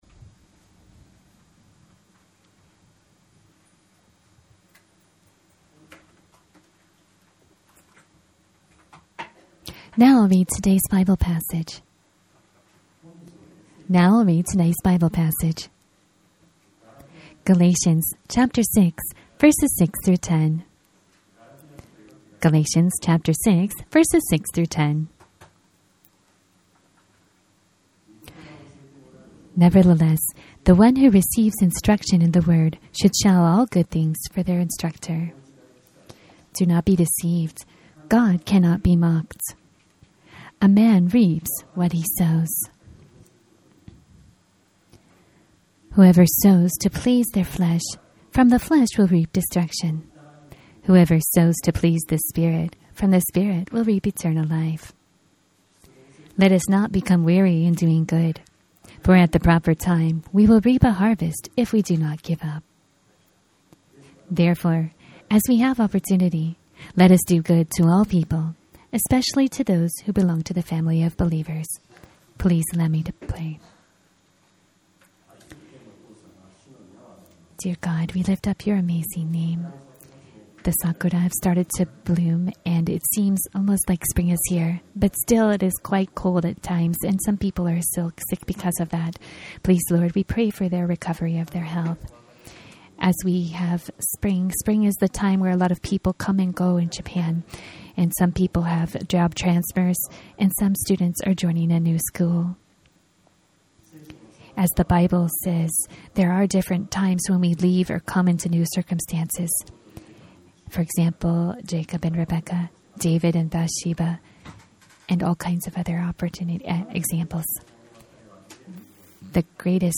Sermons of Minemachi Christ Church.MCC delivers translated sermons from the Sunday service.